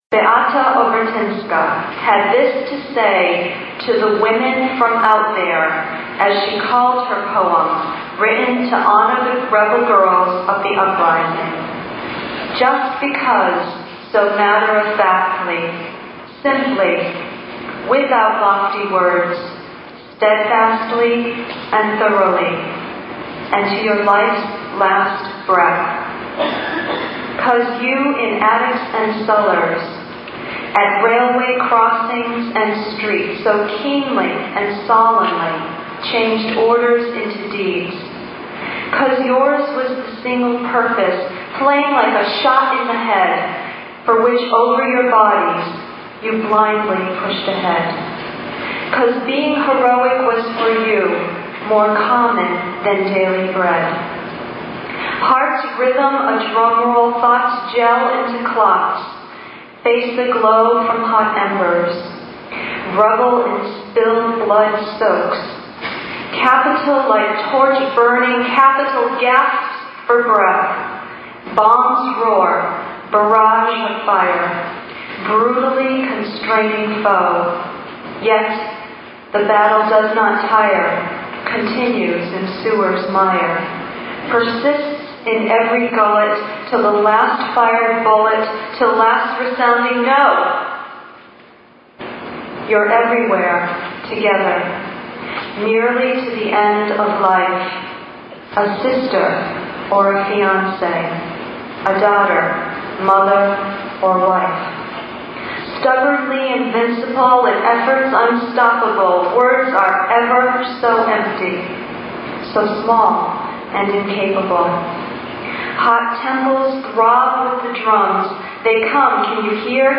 Presented on August 14th, 1994, at the St. Stanislaw's School in Chicopee, MA.
Keyboards, Accordion, and Musical Direction: